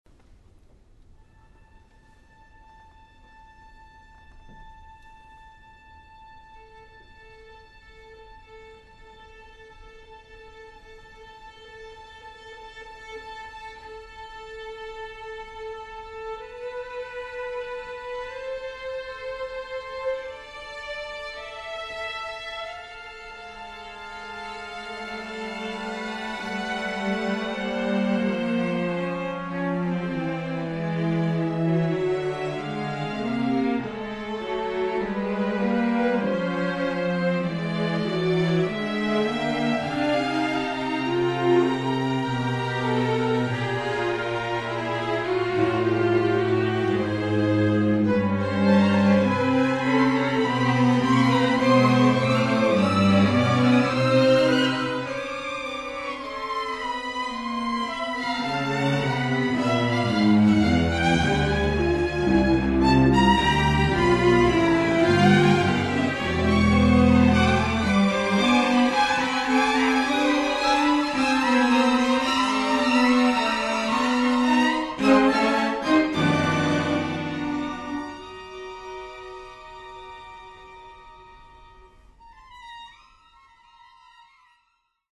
Orkester